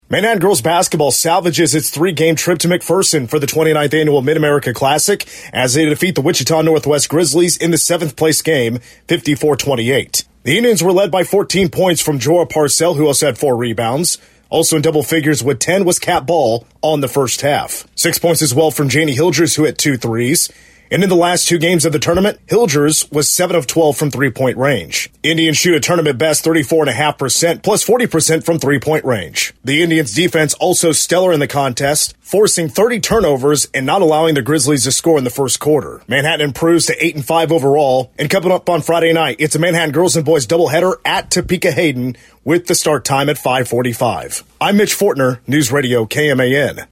Game Recap